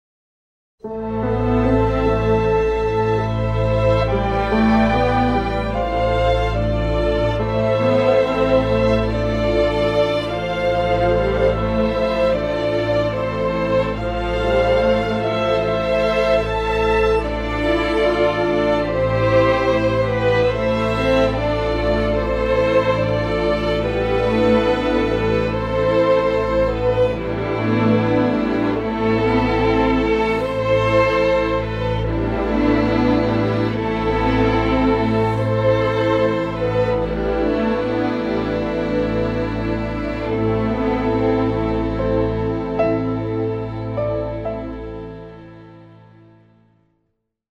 soundtrack